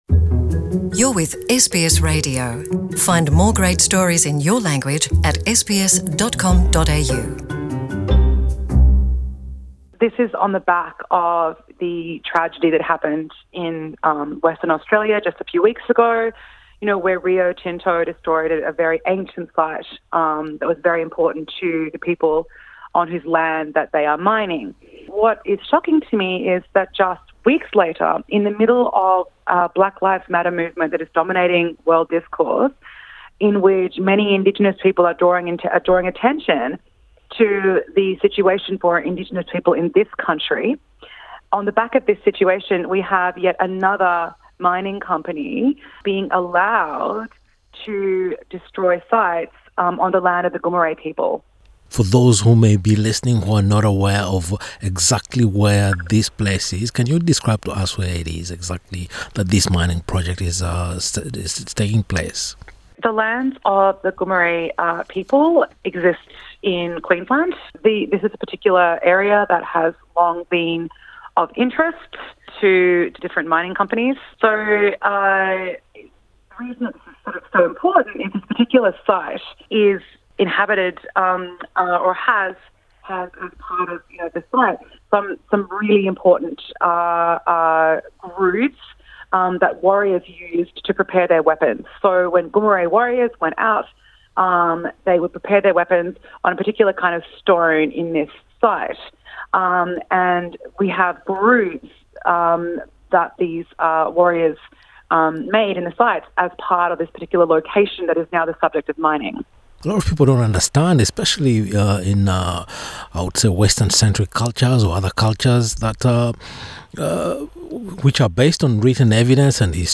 In a conversation with NITV Radio